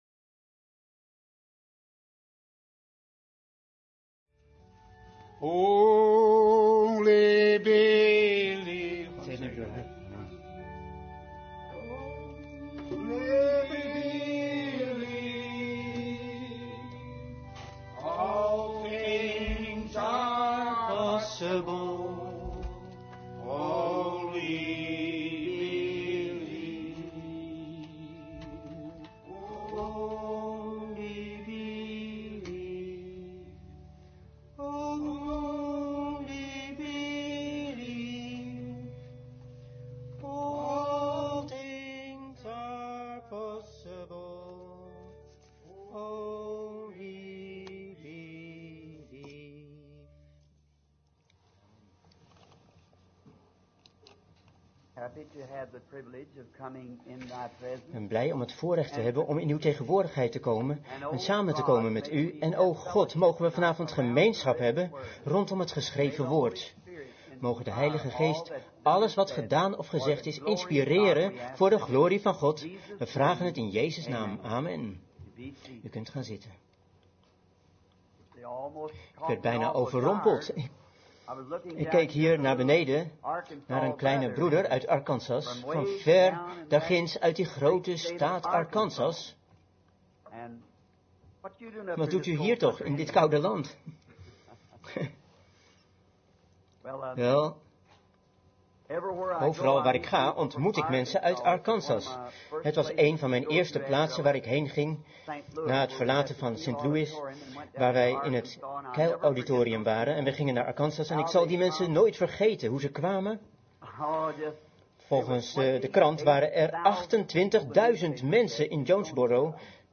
Vertaalde prediking "The tower of Babel" door William Marrion Branham te The Hippodrome, Waterloo, Iowa, USA, 's avonds op dinsdag 28 januari 1958